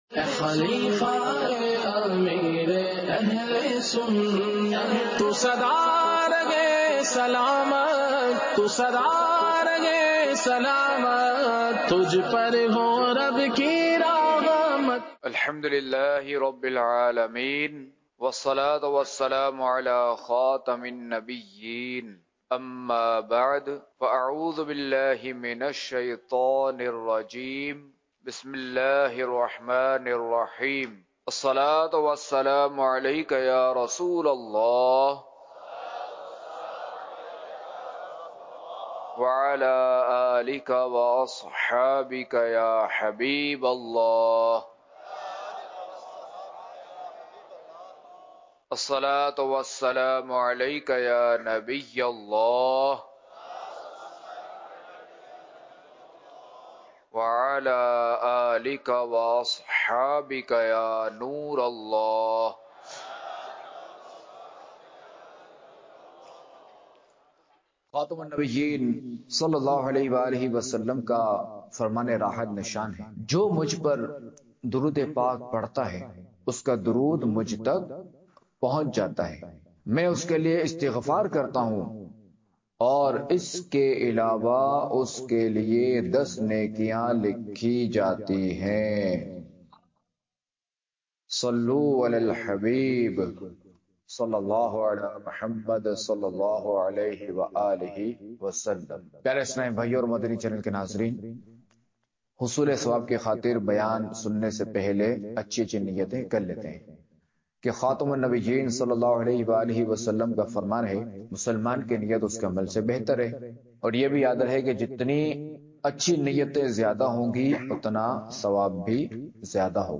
خلیفہ امیر اہلسنت کے بیانات - اصحابِ کہف رحمۃ اللہ علیھم کا واقعہ